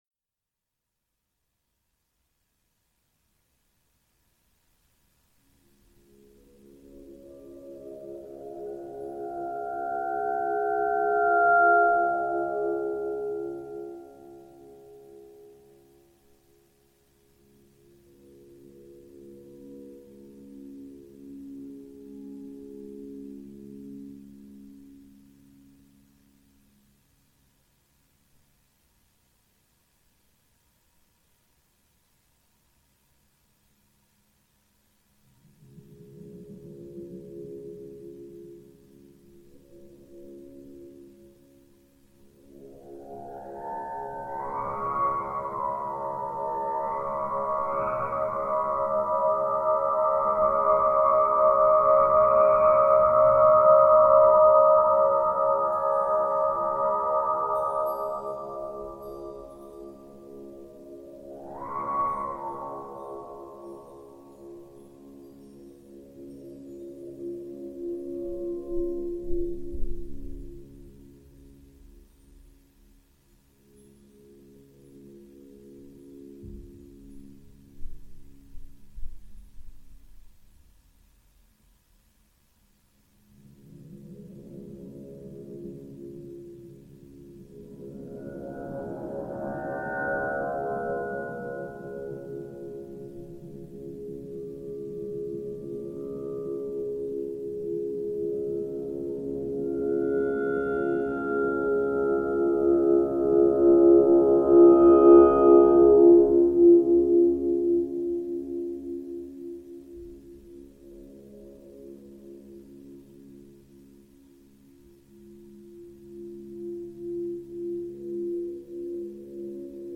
Updated Aeolian Harp recording